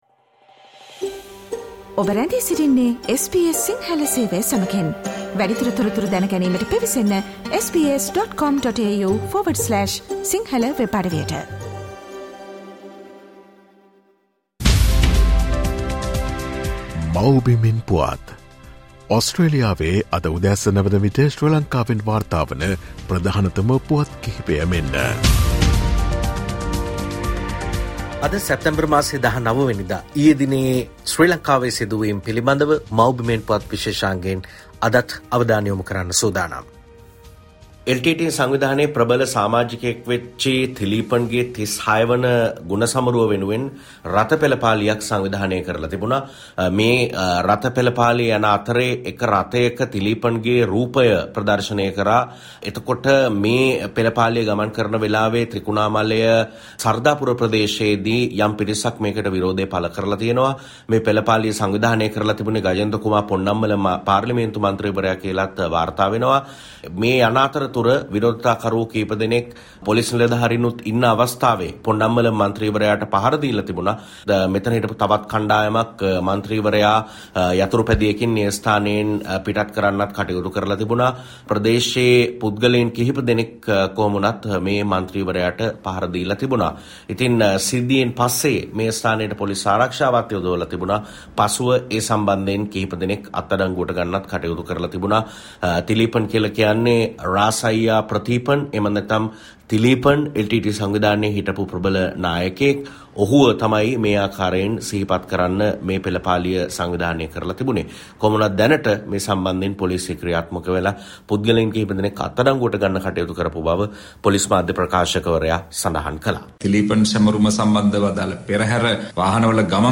The committee looking into Channel 4 is useless Cardinal Malcolm Ranjith says: Homeland News Report on 19 Sep